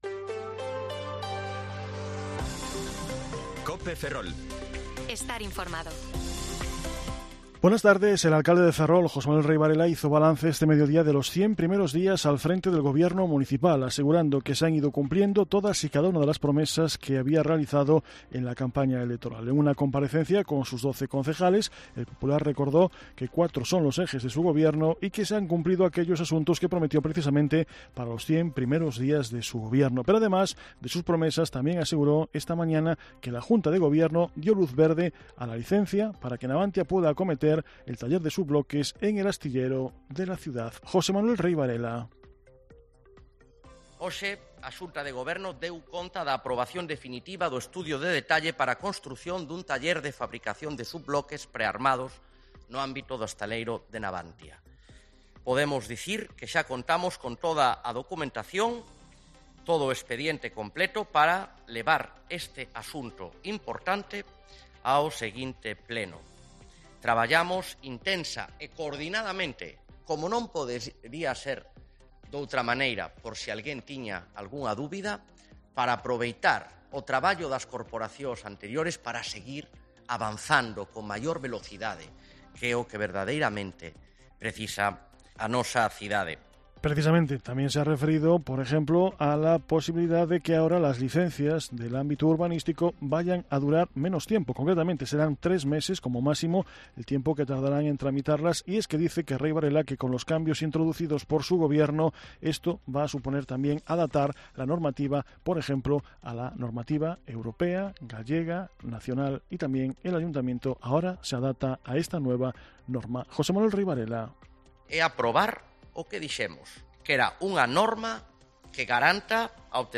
Informativo Mediodía COPE Ferrol 25/9/2023 (De 14,20 a 14,30 horas)